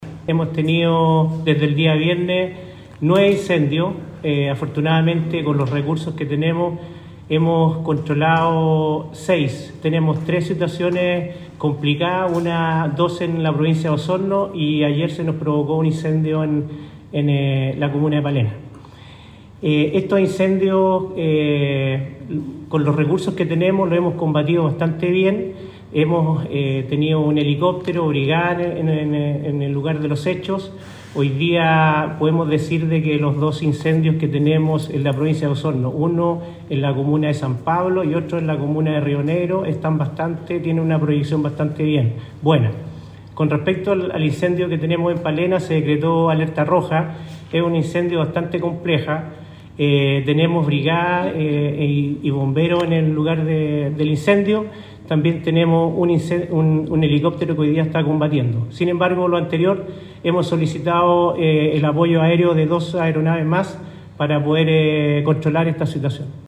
En tanto, el director regional de Conaf, Miguel Ángel Leiva también se refirió a la complejidad que representa el incendio forestal en el sector El Tigre, de Palena.